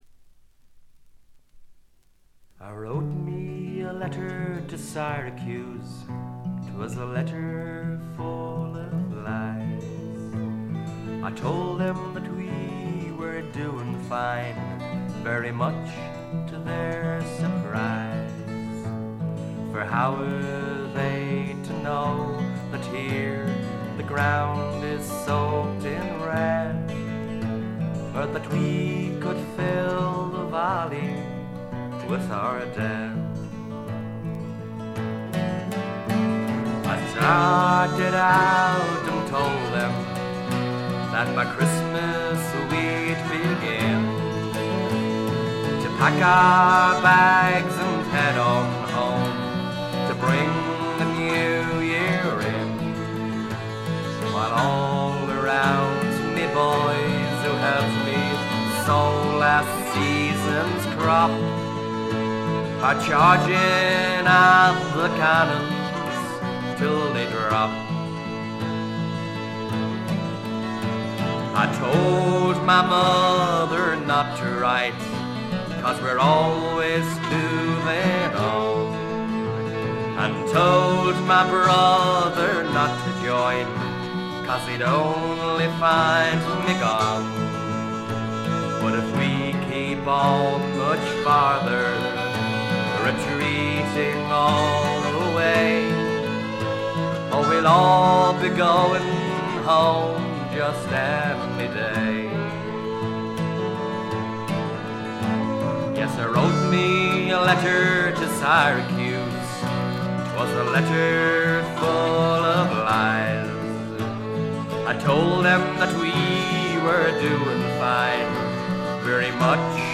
ほとんどノイズ感無し。
アイリッシュ・フォーク基本中の基本です。
中身は哀切なヴォイスが切々と迫る名盤。
試聴曲は現品からの取り込み音源です。